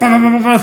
Play, download and share 磅磅磅磅磅 original sound button!!!!
bang-bang-bang-bang-bang.mp3